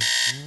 • ALARM BUZZ.wav
ALARM_BUZZ_bbi.wav